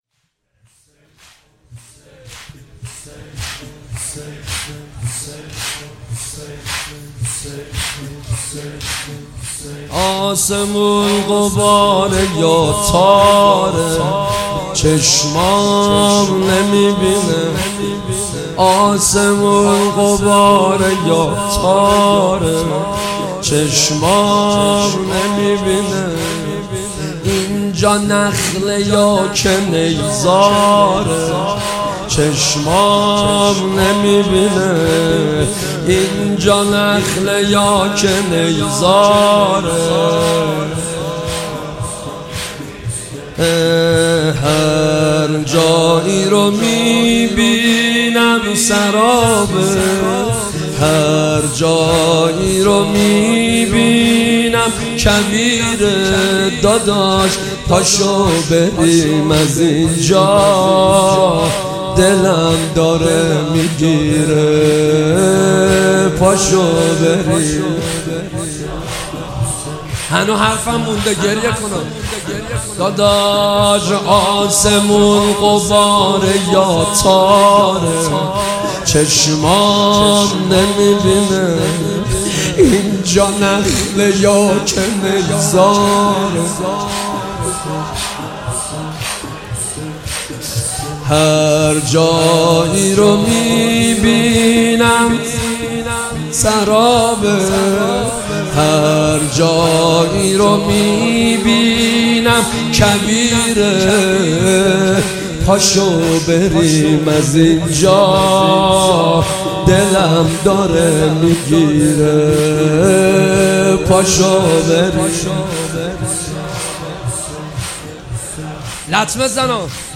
خیمه گاه - هیئت نوجوانان خیمة الانتظار زنجان - زمینه ـ آسمـون غباره یا تاره